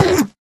Sound / Minecraft / mob / endermen / hit2.ogg